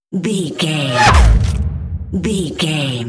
Sci fi whoosh to hit
Sound Effects
dark
futuristic
intense
woosh to hit